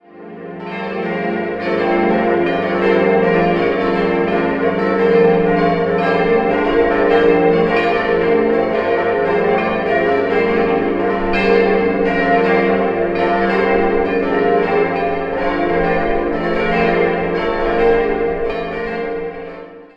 5-stimmiges Geläut: f'-g'-a'-c''-d'' (verzogen) Die beiden großen Glocken wurden 1983 von Petit&Edelbrock gegossen, die Glocke 3 und 4 goss Johannes Reuter im Jahr 1623 und die kleinste stammt von Cort van Stommel aus dem Jahr 1666.